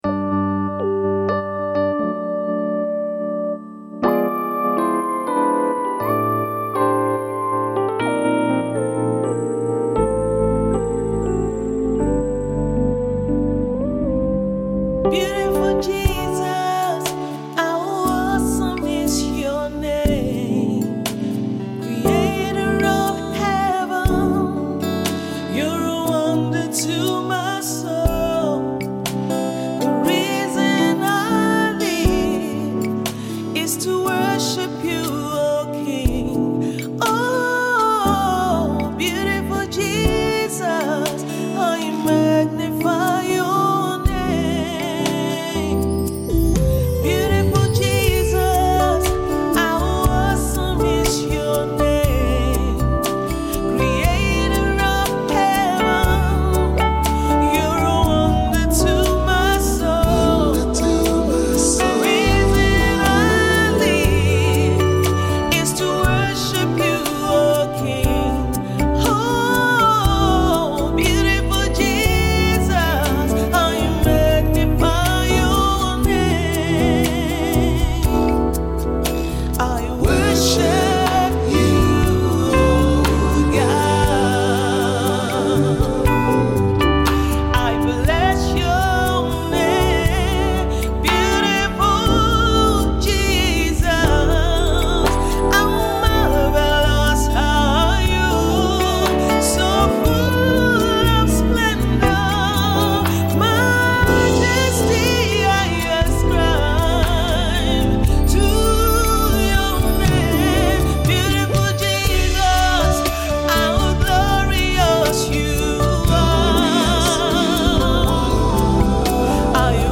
This worship track